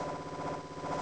tfworld-hackathon / output / piano / 2-9.wav